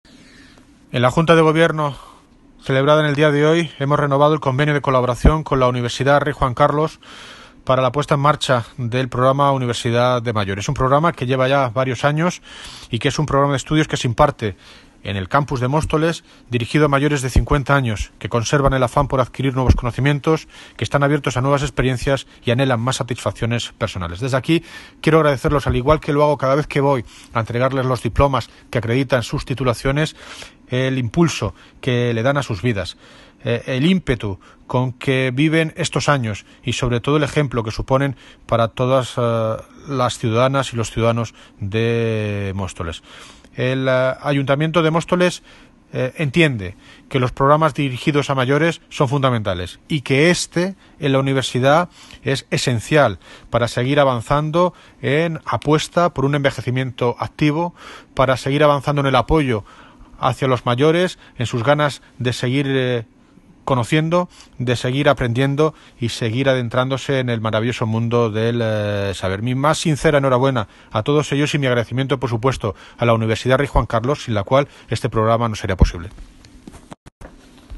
Audio - David Lucas (Alcalde de Móstoles) Sobre Universidad de Mayores
Audio - David Lucas (Alcalde de Móstoles) Sobre Universidad de Mayores.mp3